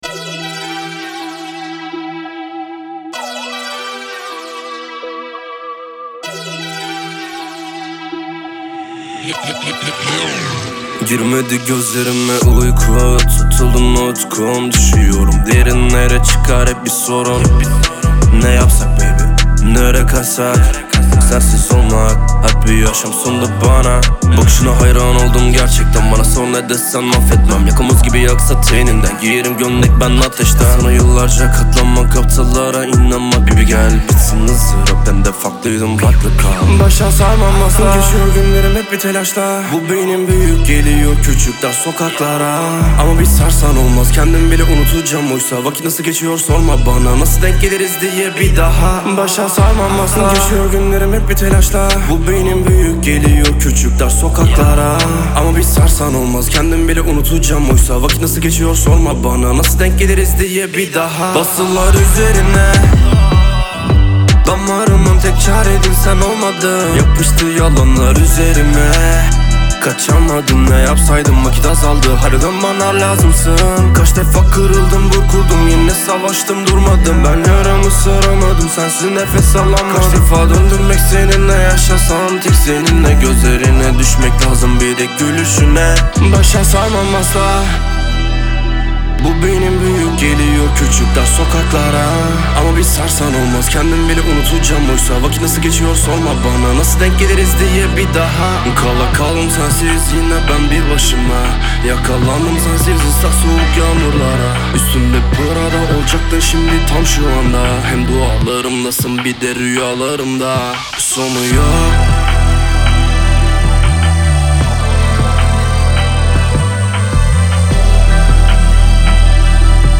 Трек размещён в разделе Рэп и хип-хоп / Турецкая музыка.